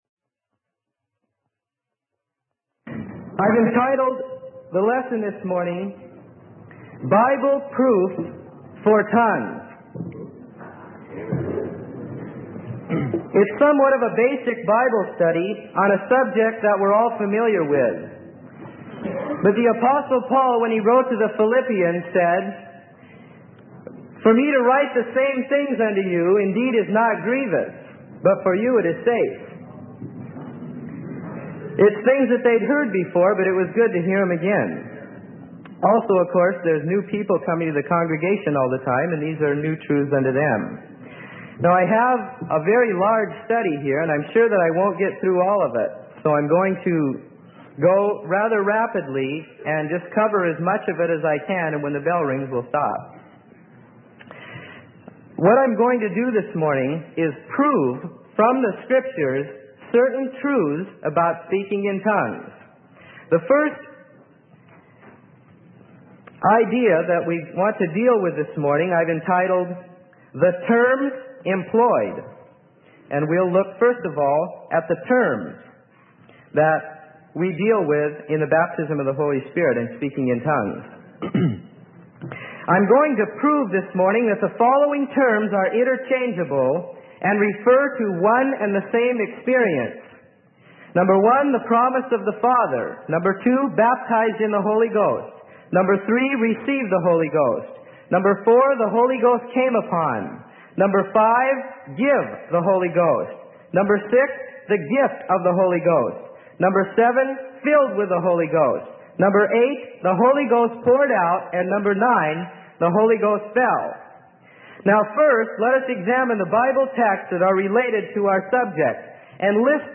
Sermon: Bible Proof for Tongues - Freely Given Online Library